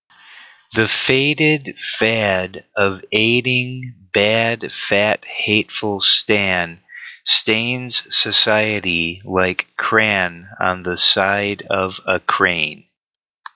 Again, each tongue twister comes with a model recording that can guide your pronunciation.
MODEL RECORDING